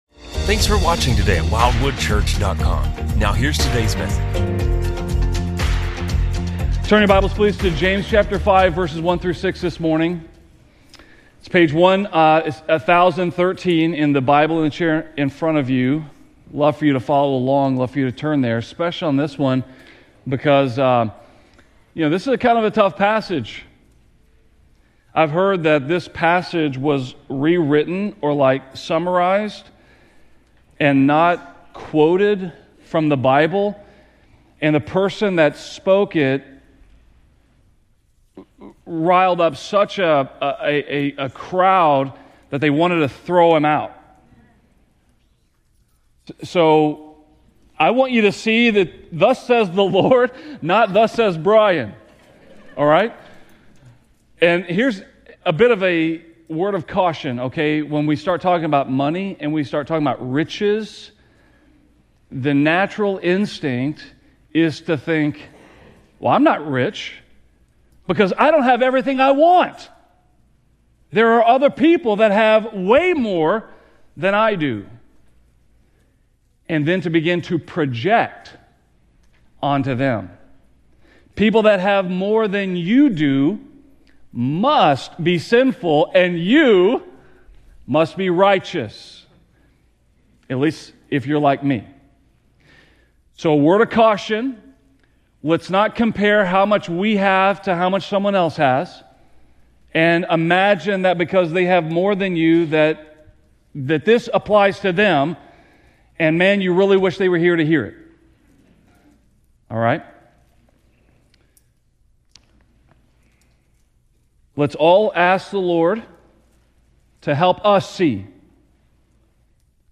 A message from the series "Wisdom From Above."